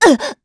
Isolet-Vox_Damage_kr_05.wav